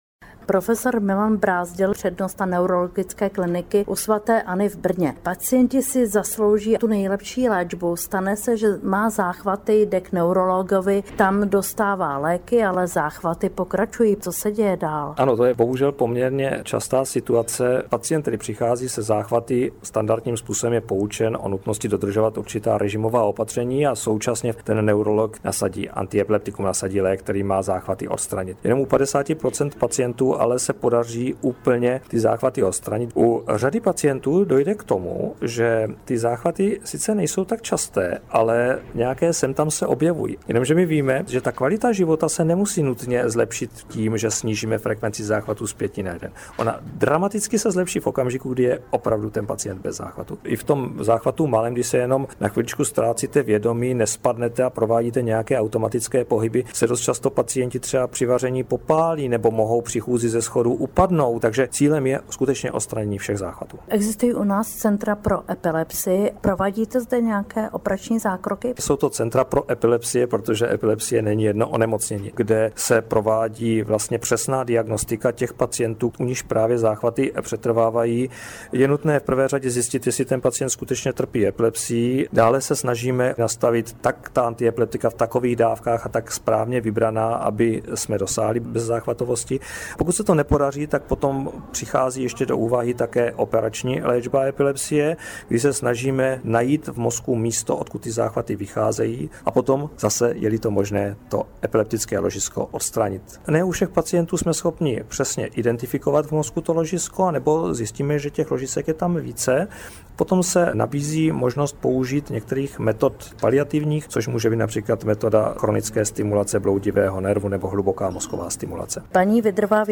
Rozhovor o epilepsii s odborníky. Epileptici mohou žít kvalitní život, jehož součástí je i sportování.